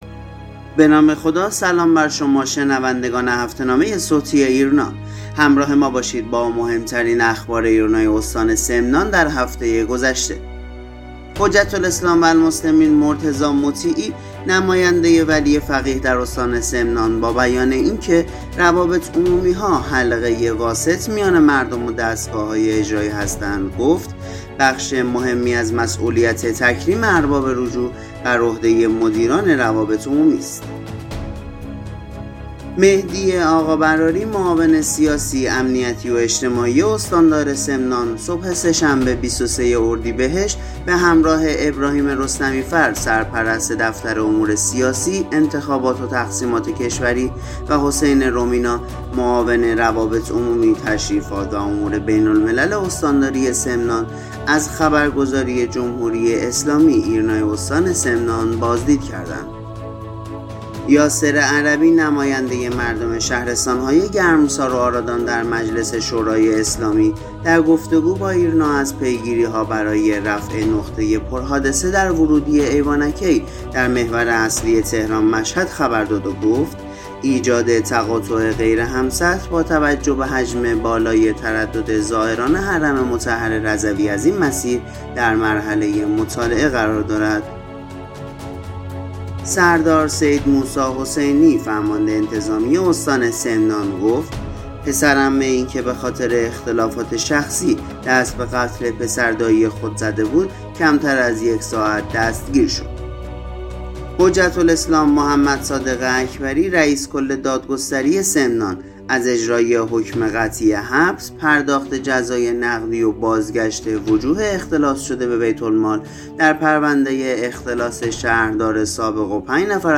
هفته نامه صوتی ایرنا سمنان | بازدید معاون استاندار از ایرنا و تاکید بر نقش روابط عمومی ها